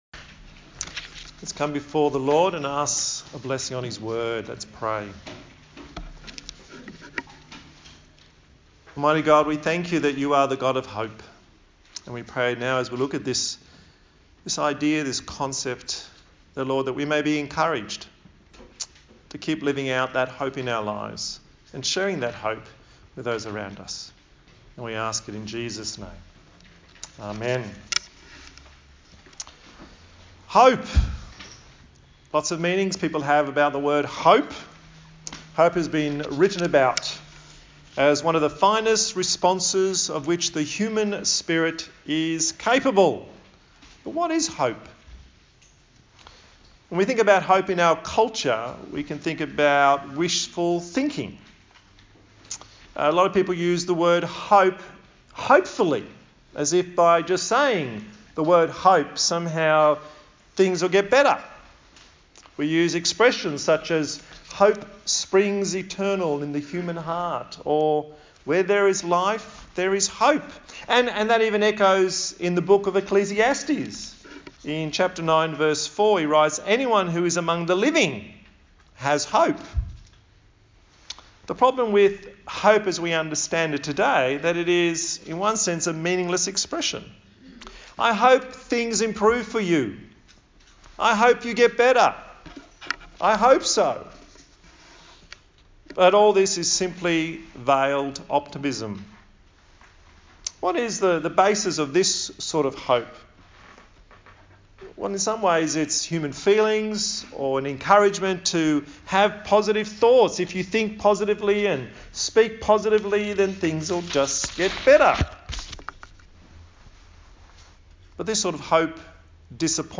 Romans Passage: Romans 5:1-11 Service Type: Sunday Morning